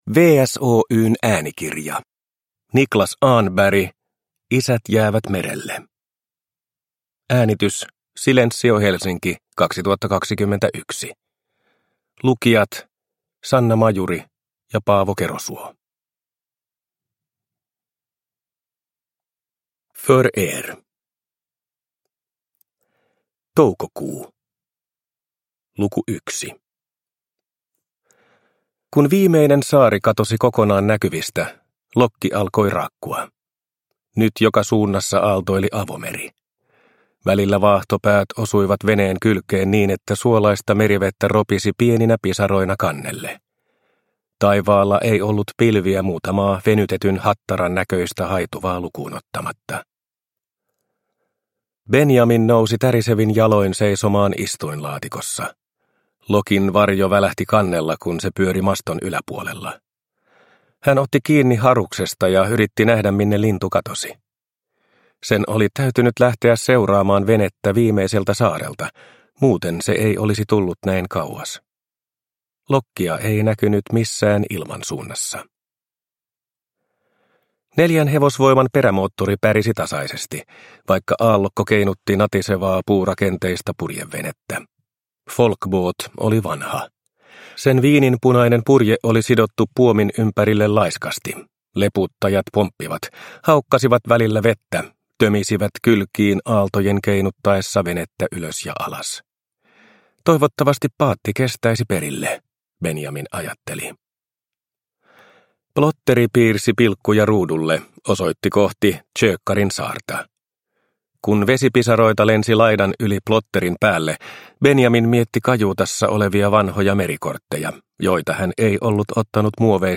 Isät jäävät merelle – Ljudbok – Laddas ner